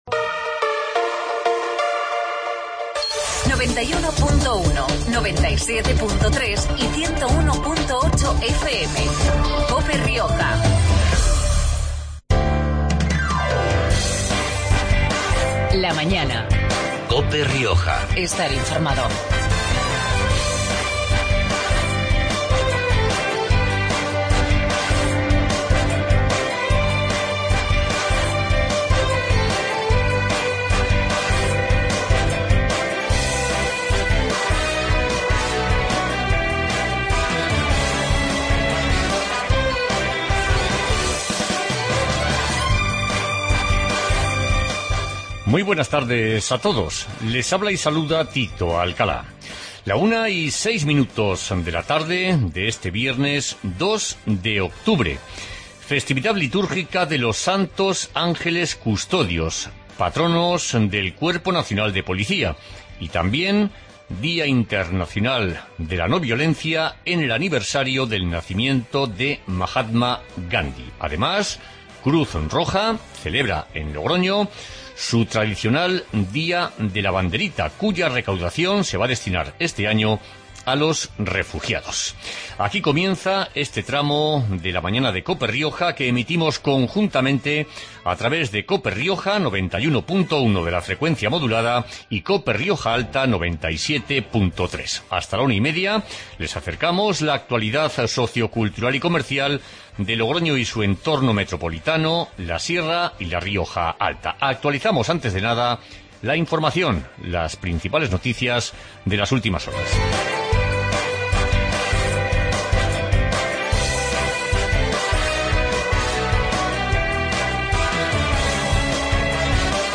Magazine de actualdiad riojana